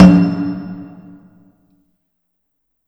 clap.wav